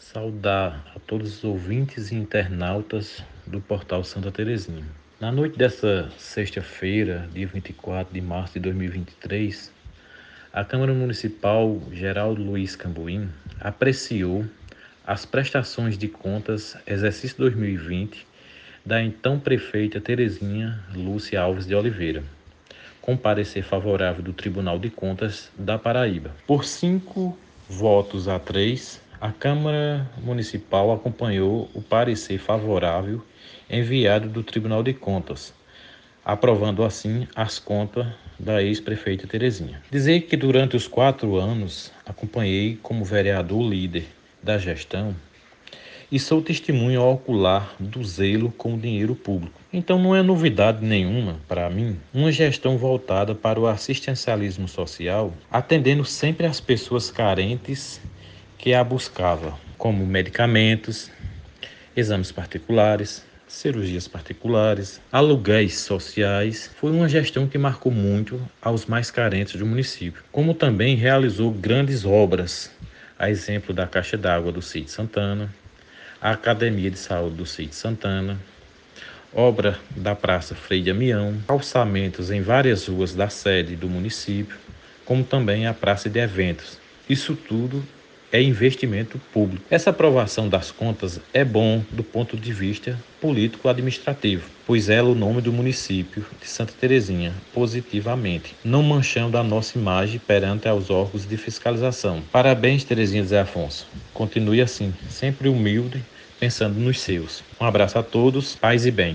O líder da Oposição na Câmara e líder durante a gestão da ex-prefeita, o vereador Edcarlos Soares, falou com o Portal Santa Teresinha e com a Rádio Conexão FM, dizendo que durante os quatro anos da gestão passada, foi testemunha do zelo com o dinheiro público. Relatou que a gestão foi dedicada ao assistencialismo social, sempre atendendo as pessoas que mais necessitavam, fazendo doações de: medicamentos, exames particulares, cirurgias particulares e aluguéis sociais.